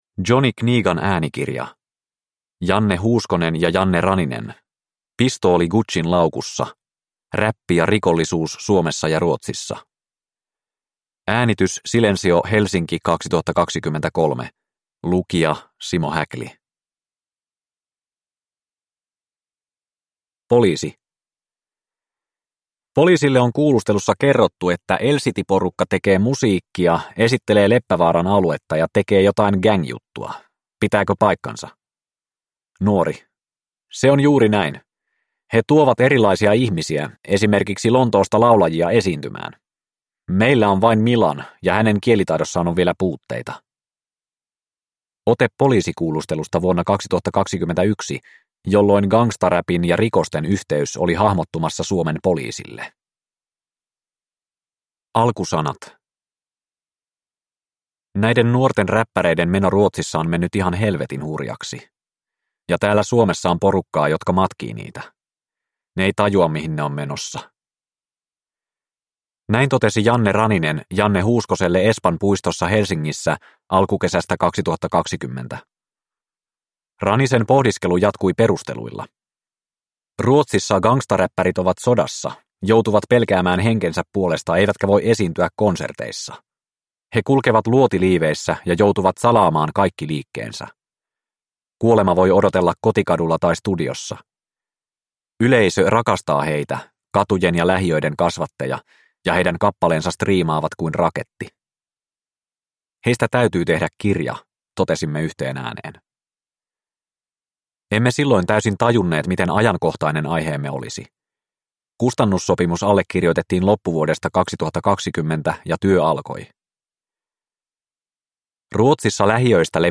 Pistooli Guccin laukussa (ljudbok) av Janne Raninen